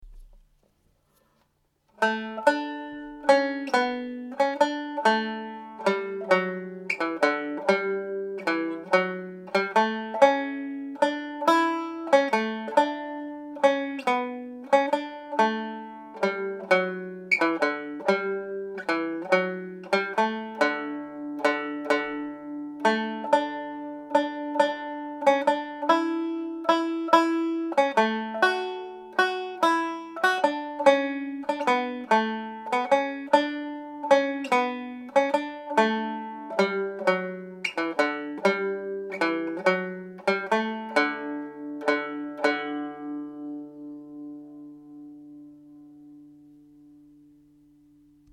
This is a beautiful slow piece of music written by Turlough O’Carolan.
Planxty Irwin played at normal speed